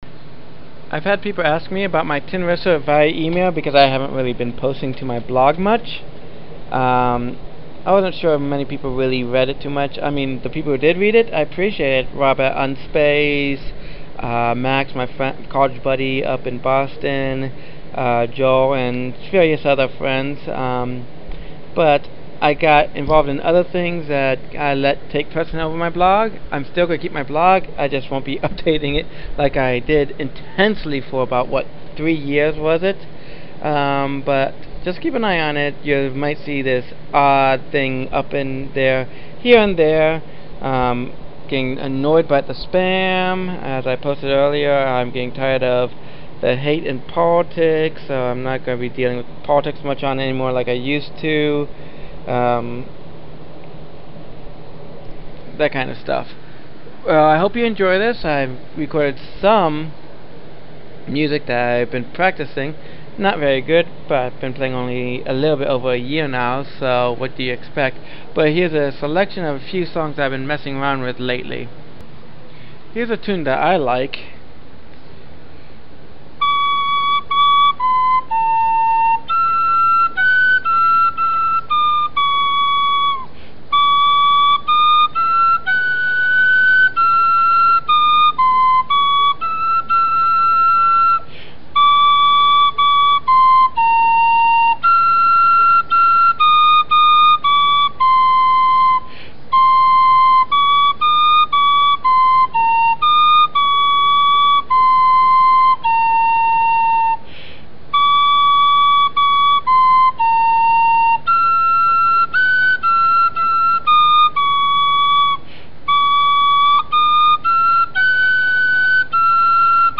Here is my attempted at playing the Spiritual Song Amazing Grace, still not sure it made it into the new Lutheran hymnal, it is a great song and all, but not a hymn.
Irish Tin Whistle
tin wistle post.mp3